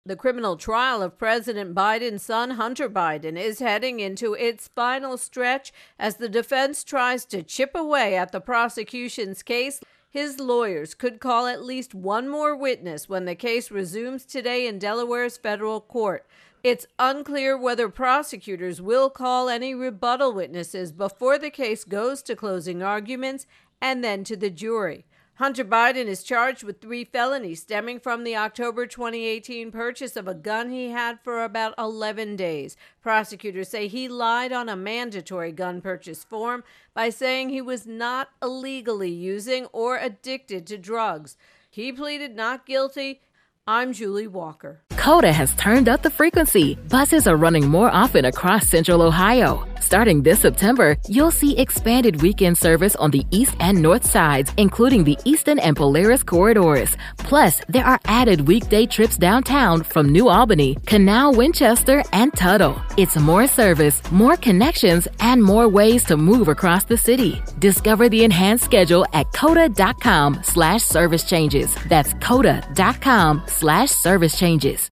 reports on Hunter Biden's gun trial.